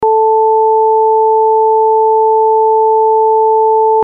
Onda seno a 440 Hz + onda seno a 880 Hz.